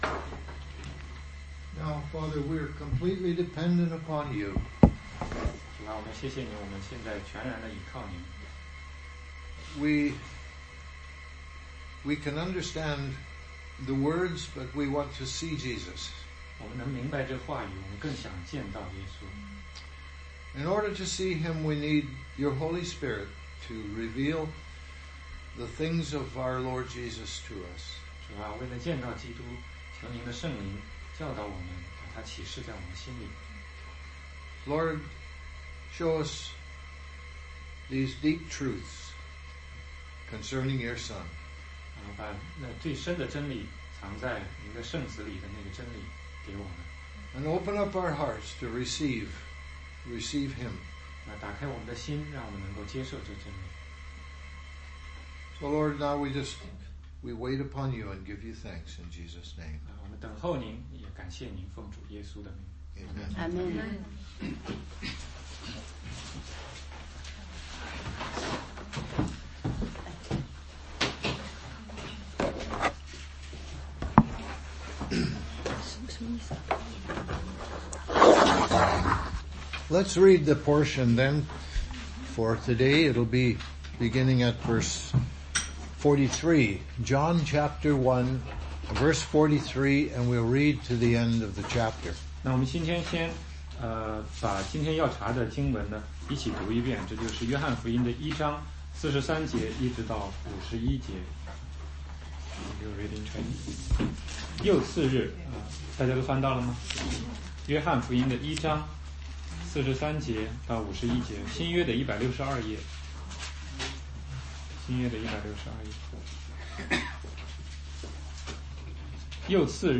16街讲道录音 - 约翰福音1章43-51